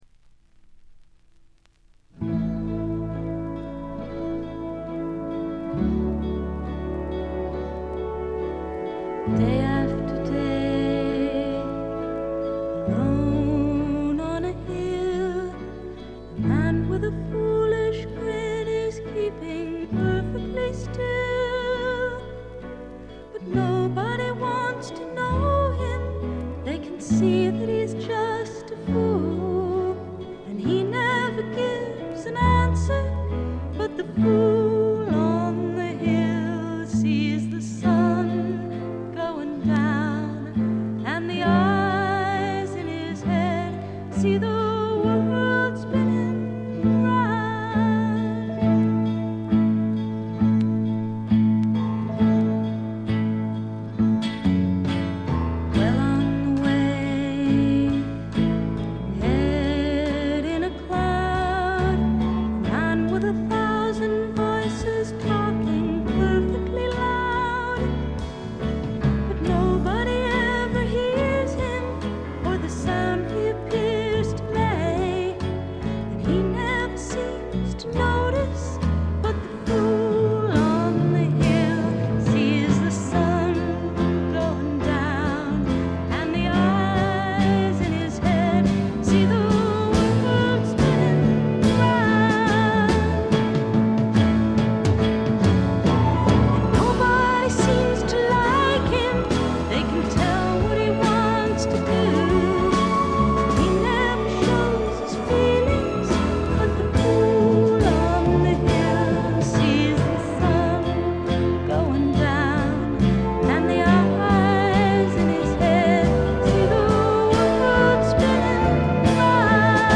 ところどころで軽いチリプチ。
ドリーミーなアメリカン・ガールポップの名作！
試聴曲は現品からの取り込み音源です。